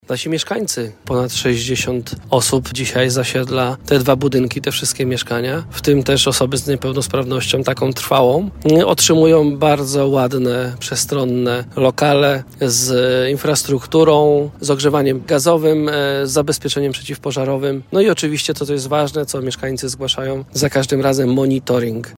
Arkadiusz Mazepa, zastępca Prezydenta Miasta Świnoujście: